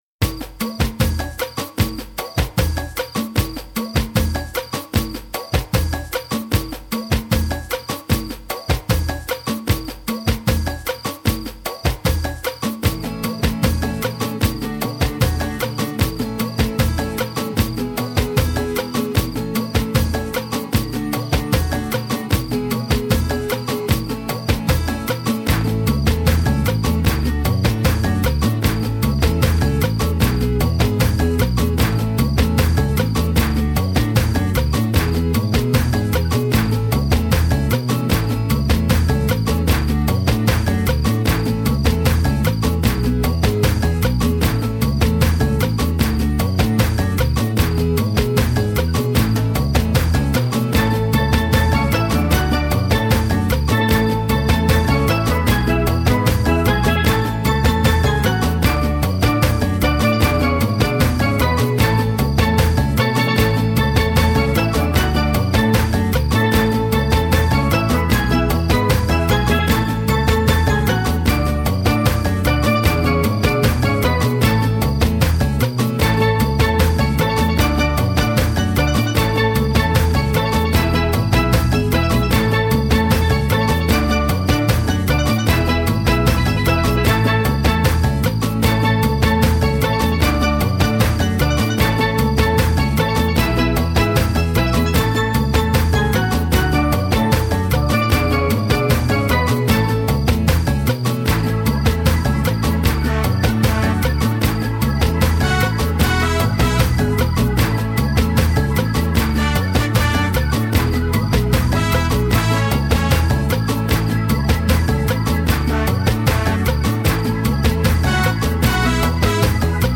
CARIBBEAN CHILLOUT AMBIENT（加勒比海风情）